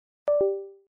На этой странице собраны звуки Windows 11 — современные системные аудиоэффекты из новой версии ОС.
Windows 11 звук подключения флешки usb